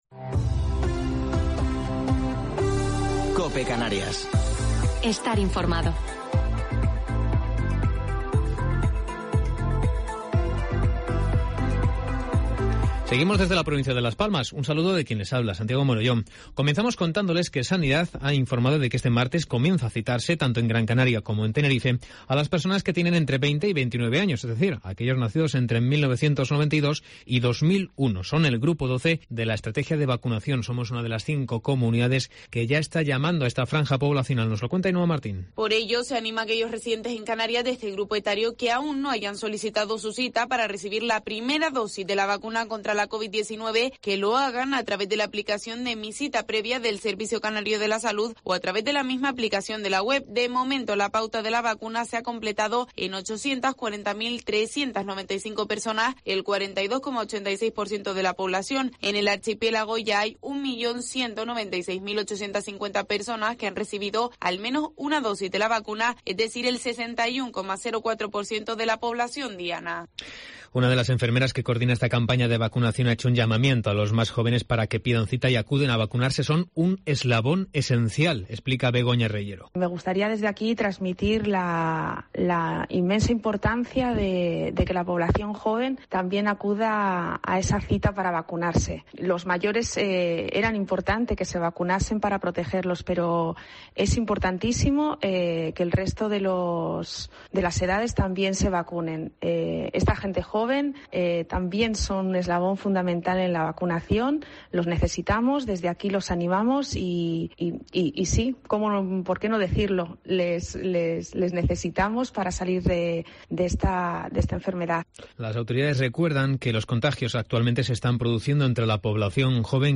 Informativo local 6 de Julio del 2021